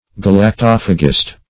Search Result for " galactophagist" : The Collaborative International Dictionary of English v.0.48: Galactophagist \Gal`ac*toph"a*gist\, n. [Gr.